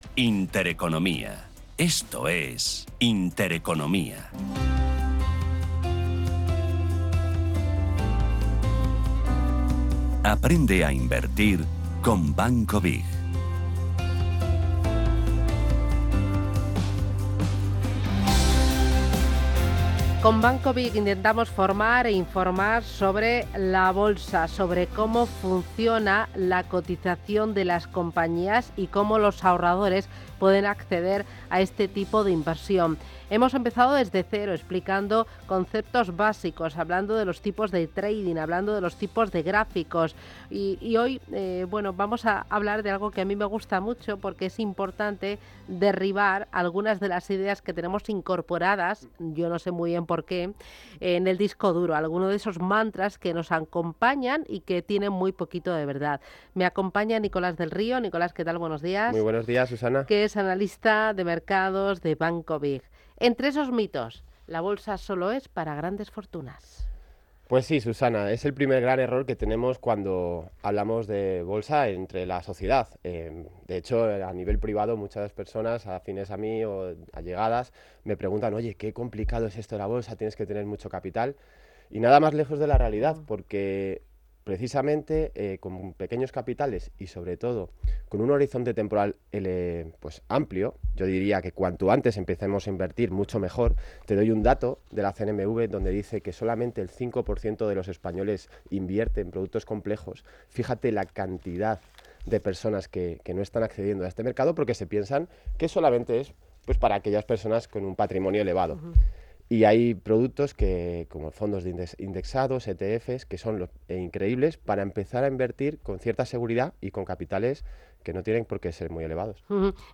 Sección "Aprende a Invertir con BiG" junto a Radio Intereconomía